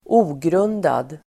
Uttal: [²'o:grun:dad]